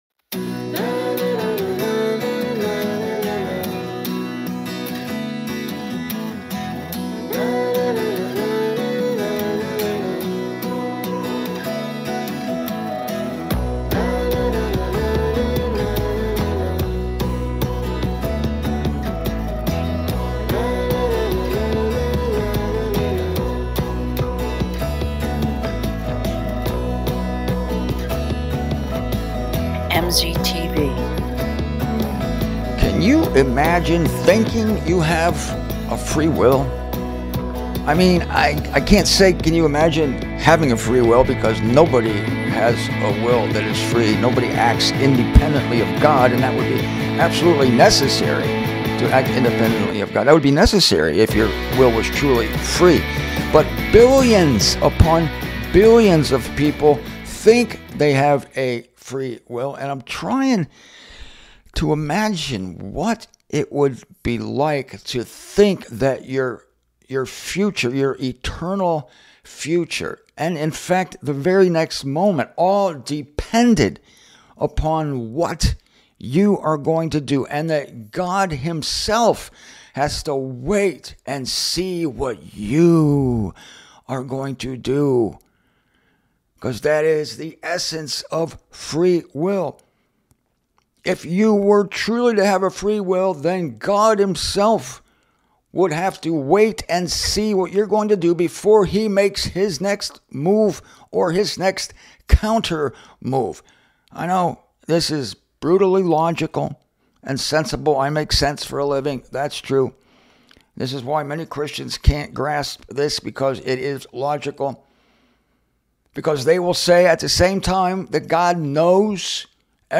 Before this conversation, however, I talk for about ten minutes on the illogical nature of human free will. It's quite a lively rant; I like it.